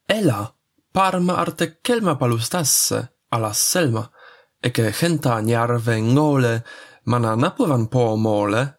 fast version